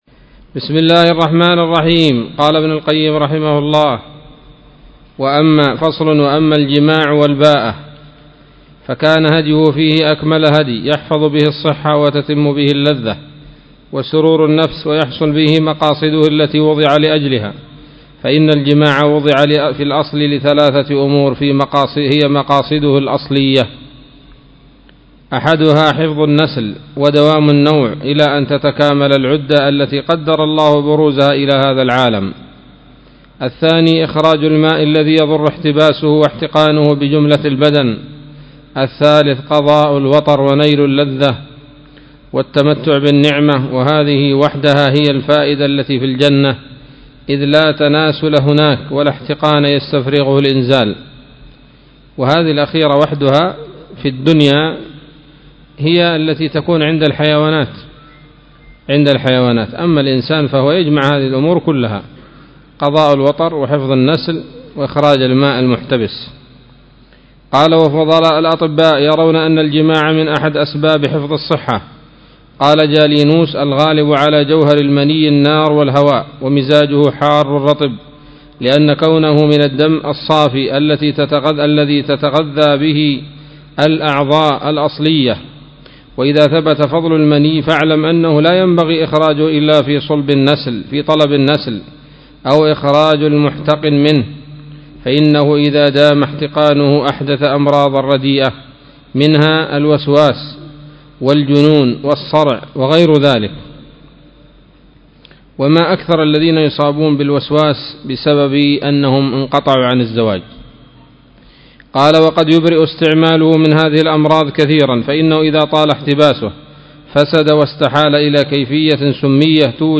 الدرس الثامن والستون من كتاب الطب النبوي لابن القيم